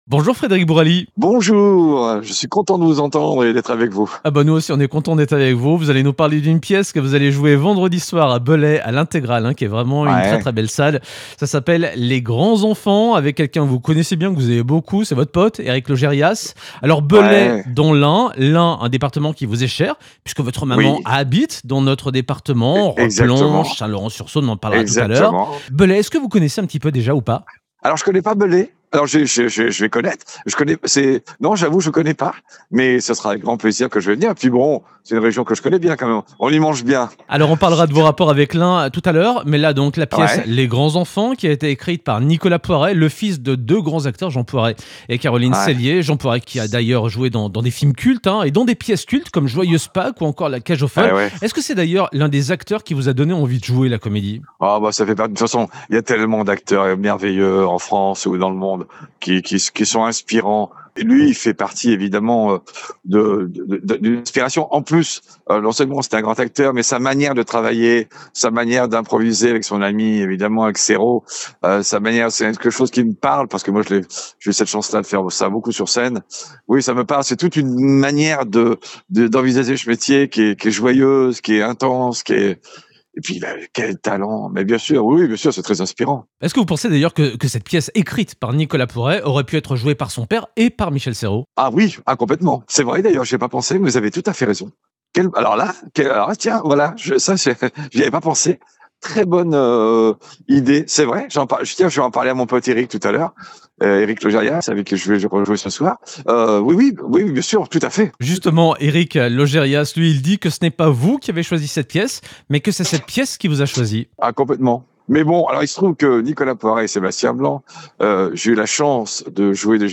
Écoutez-le évoquer cette pièce, mais aussi Scènes de ménages sur M6, son accident avec un fer à repasser, ainsi que ses liens forts avec notre département de l’Ain et la commune de Saint-Laurent-sur-Saône.